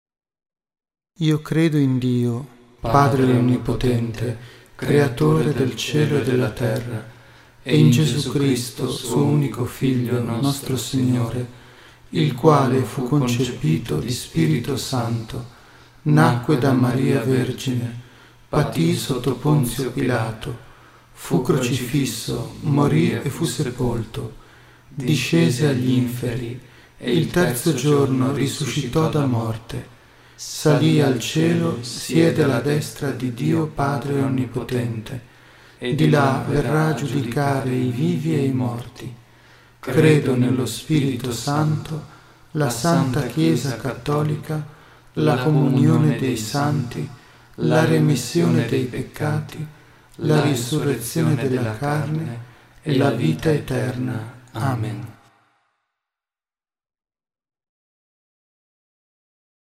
registrazione in studio
Il Santo Rosario in mp3